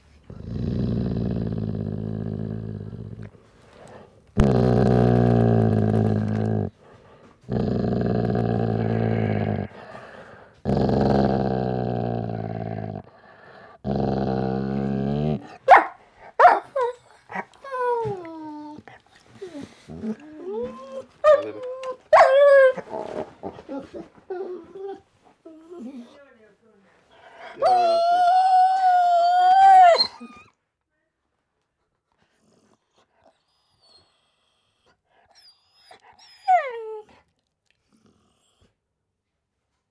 dog
bark digital-clip dog growl sound-effects whine yawn sound effect free sound royalty free Sound Effects